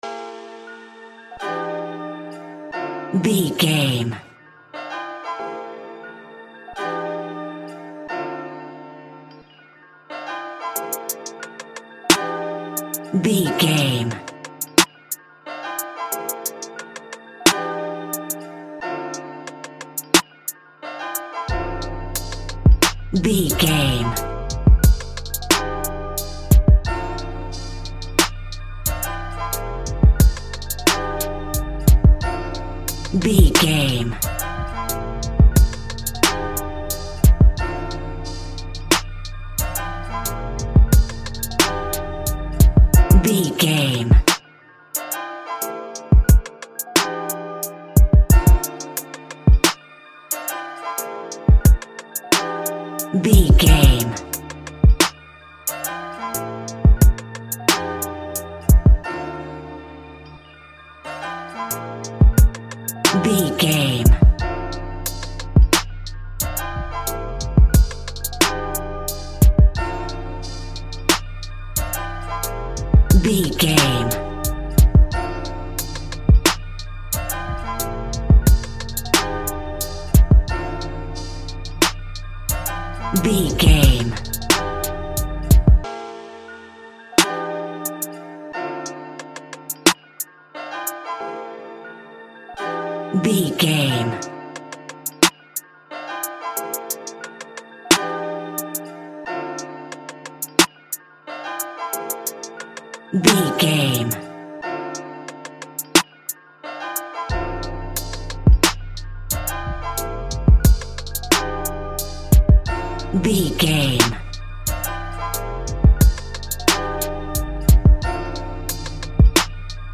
Ionian/Major
drums
smooth
mellow
soothing